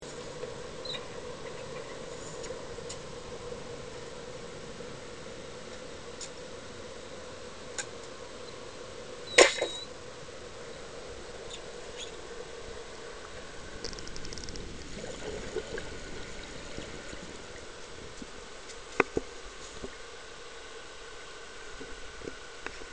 Una volta aperto il file avremo a disposizione l'onda su cui si possono visualizzare i punti corrispondenti alla partenza dell'asta ( in genere più facilmente riconoscibile ) e di impatto sul bersaglio ( in genere di ampiezza minore ) .
Nel caso in questione avremo un valore di 0,232370 secondi ( valore preciso al milionesimo di secondo : forse un pò troppo !!!!!!!! :-) approssimiamolo a 0,232 )
filmato_tiro.mp3